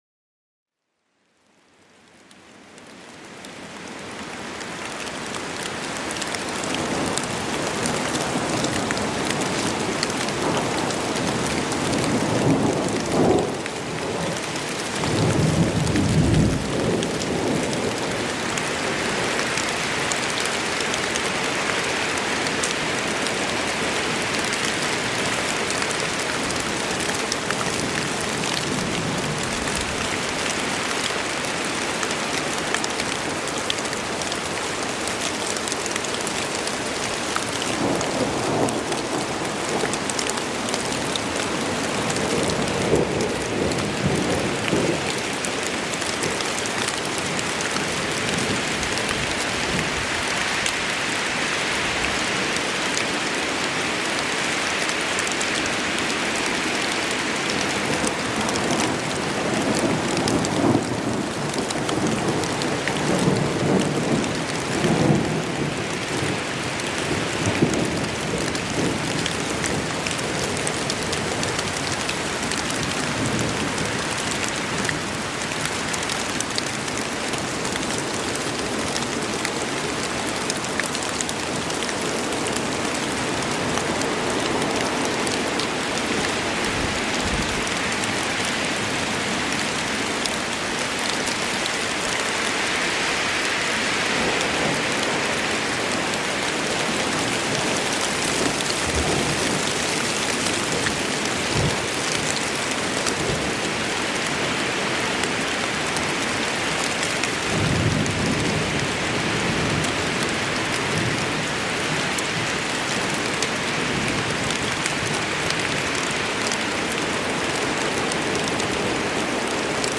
SÜCHTIG-WARNUNG: Dieser Waldsturm-Sound macht abhängig von Entspannung